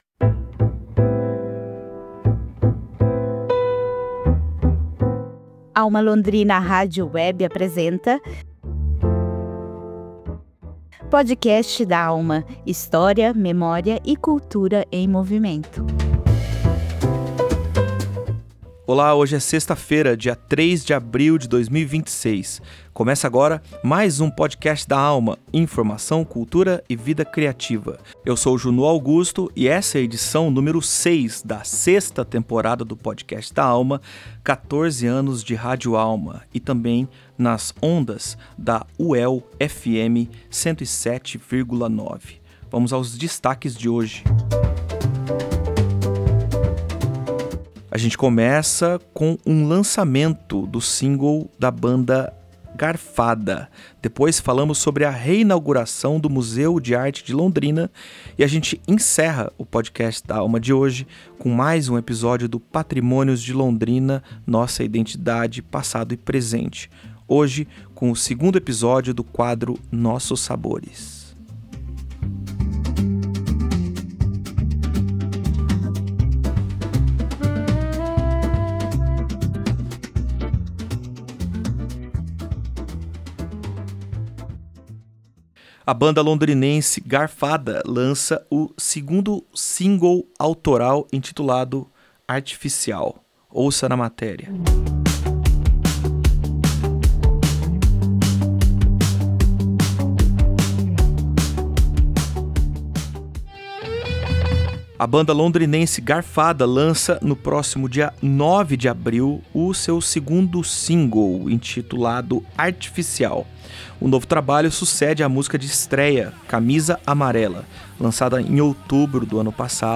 Além disso, o programa traz a faixa, exclusiva aos ouvintes da AlmA Londrina Rádio Web.